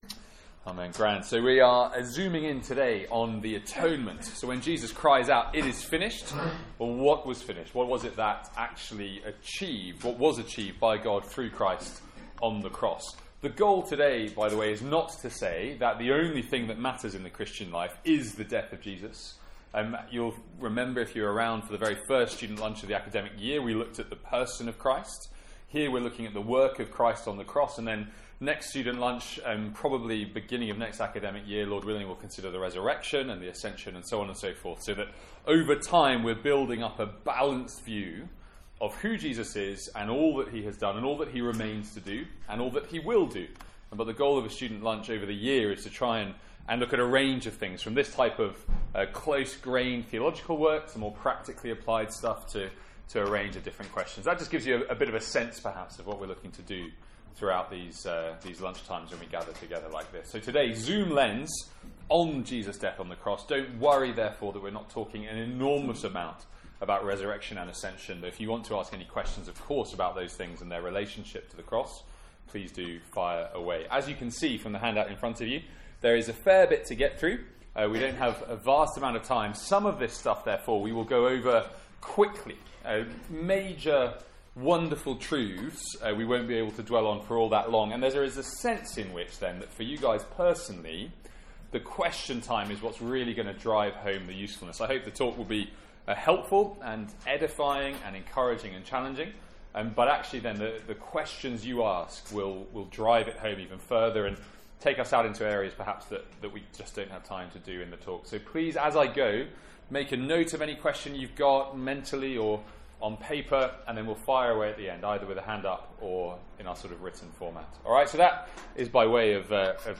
From our student lunch on 2nd April 2017.